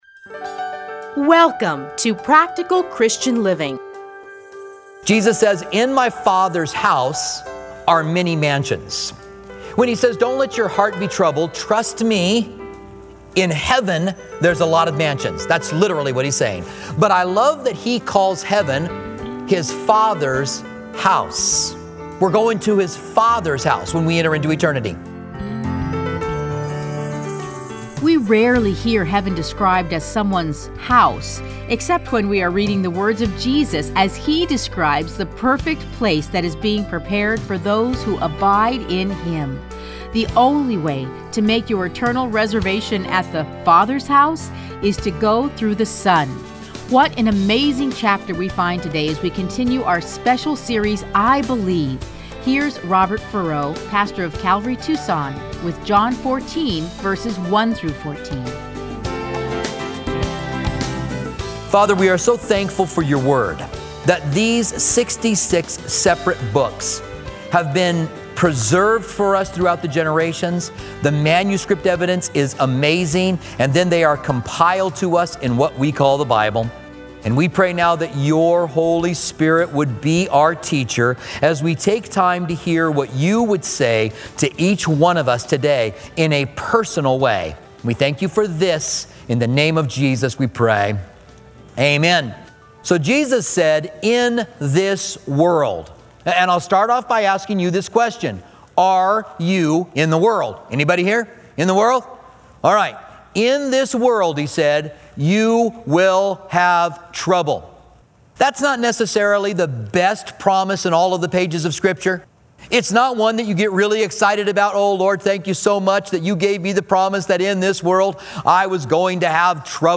Listen to a teaching from John 14:1-14.